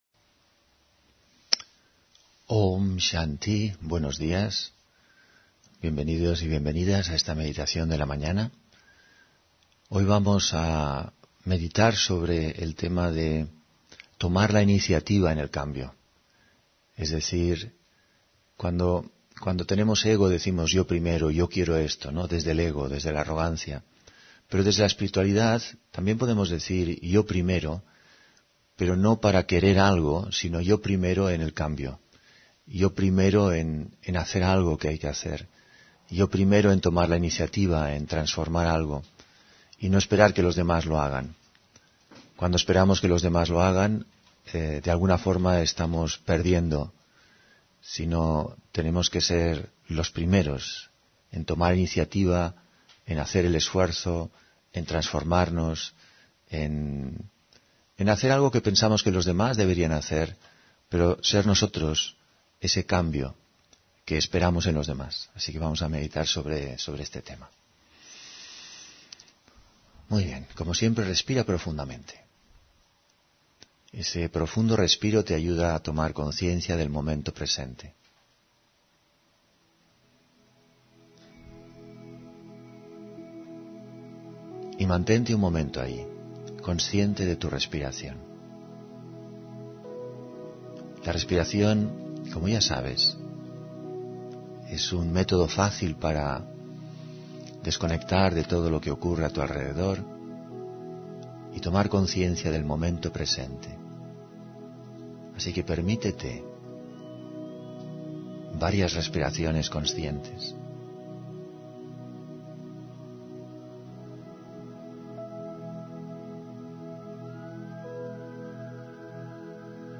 Meditación de la mañana